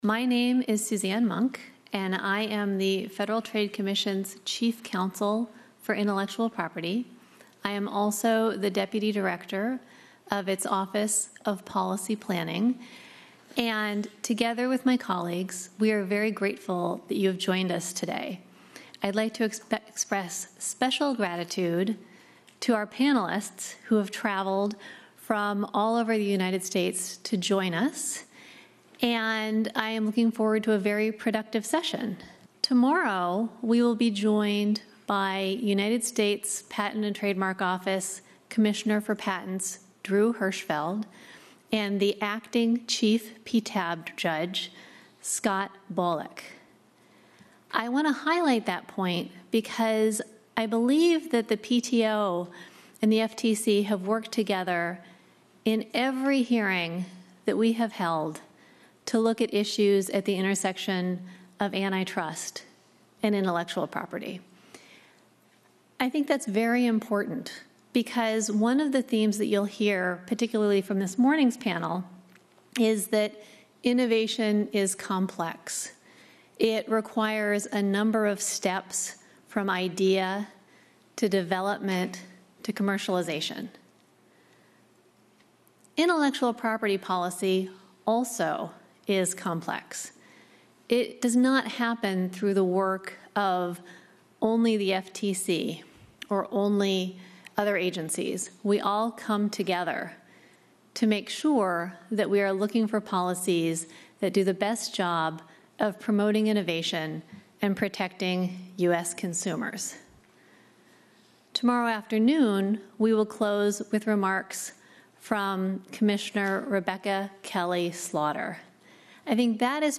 Welcome and Introductory Remarks